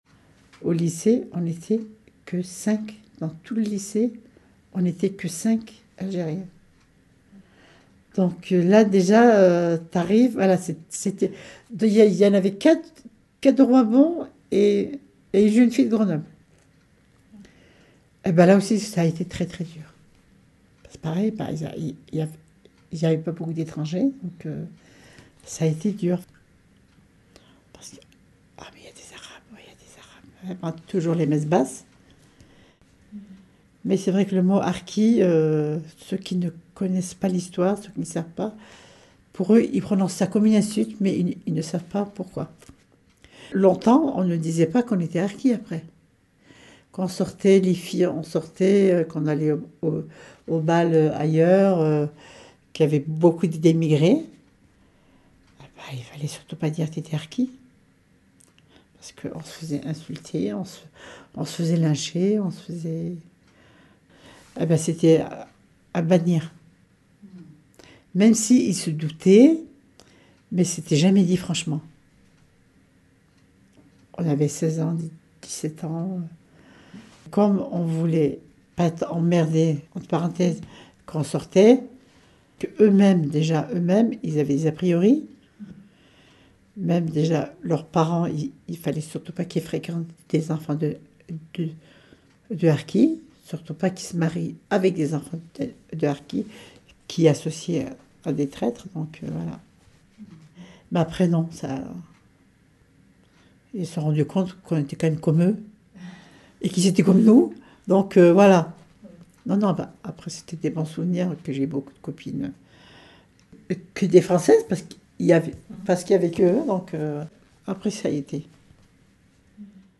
Extraits sonores d’une série d’entretiens réalisés pour la Commission nationale indépendante de reconnaissance et de réparation des préjudices subis par les Harkis
Entretien réalisé en décembre 2023 à Saint-Siméon-de-Bressieux